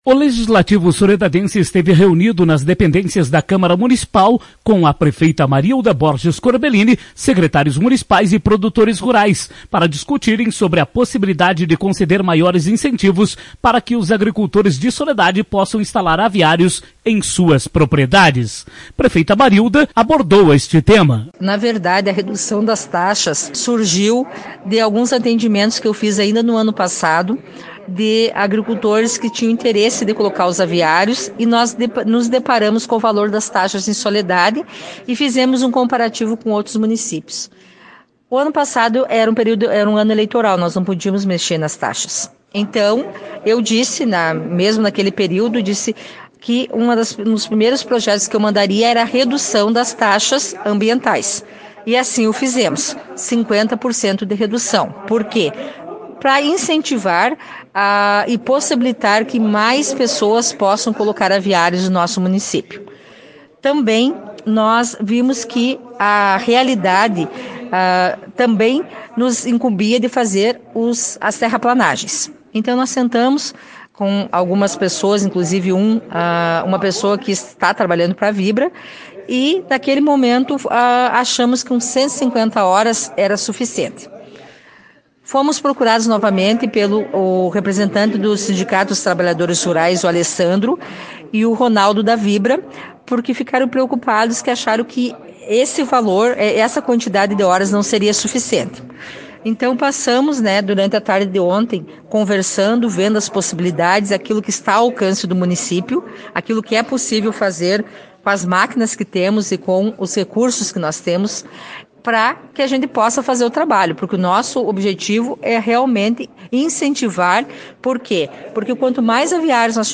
Ouça a entrevista com a prefeita Marilda Borges Corbelini, na íntegra, no player de áudio acima.